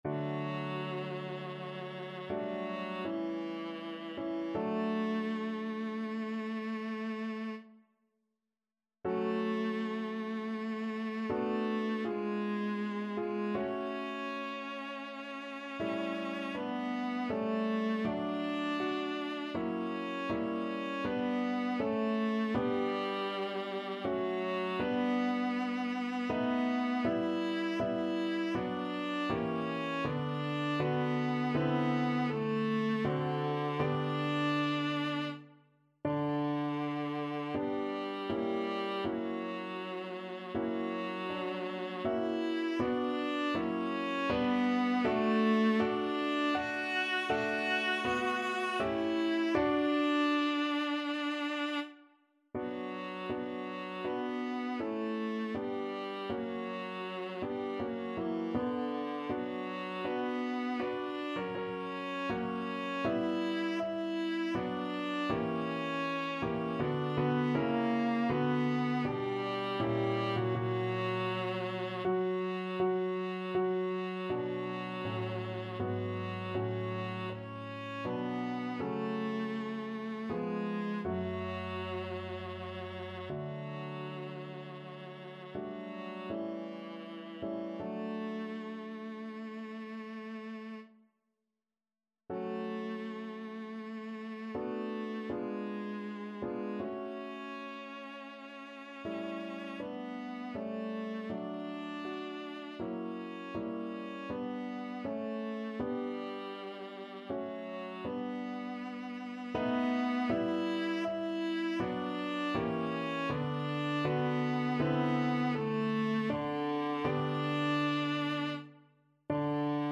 Free Sheet music for Viola
D major (Sounding Pitch) (View more D major Music for Viola )
3/4 (View more 3/4 Music)
~ = 80 Andante ma non lento
Classical (View more Classical Viola Music)